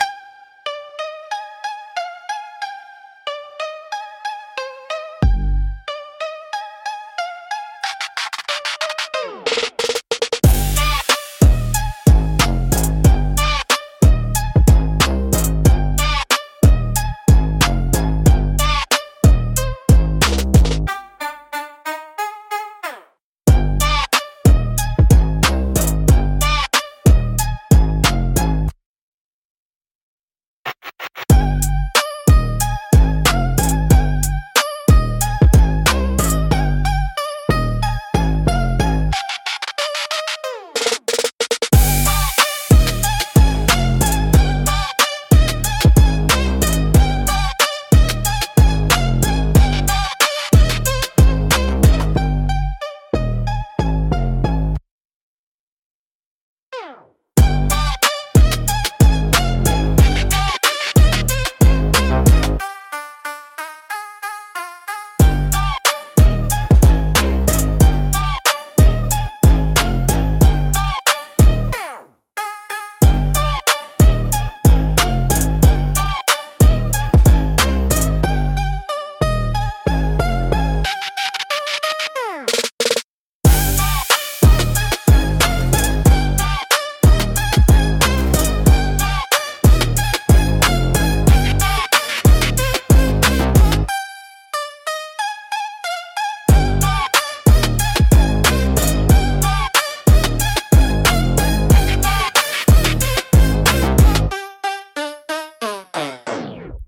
Instrumental - Block Party Anthem